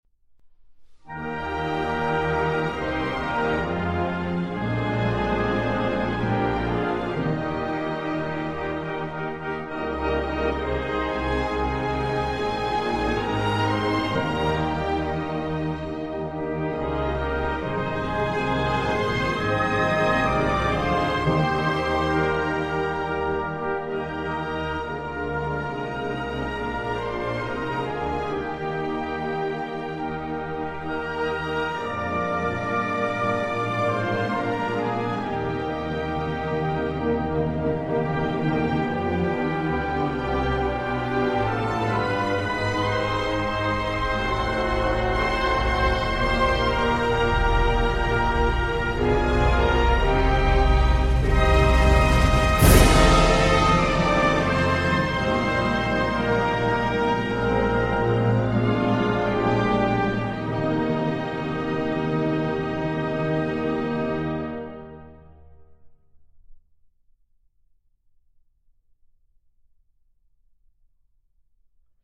Czech_anthem.mp3